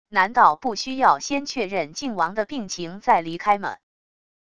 难道不需要先确认靖王的病情再离开么wav音频生成系统WAV Audio Player